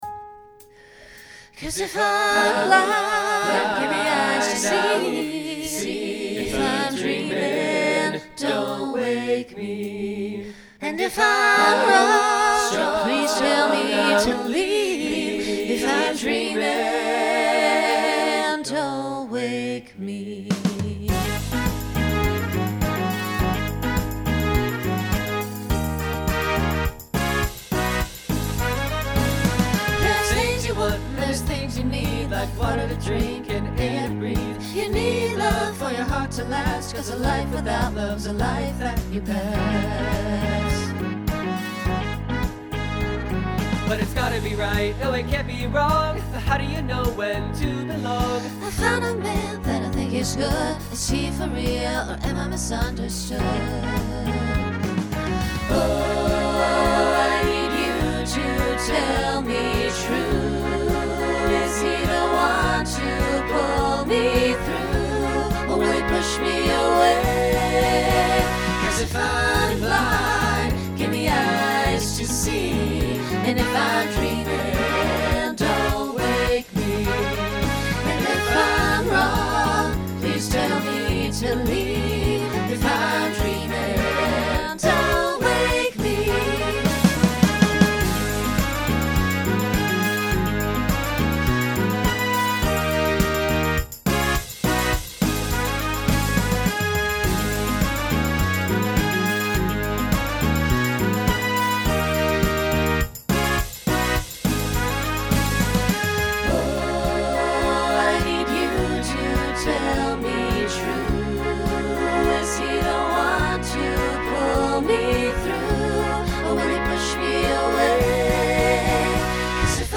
Genre Folk , Rock Instrumental combo
Voicing SATB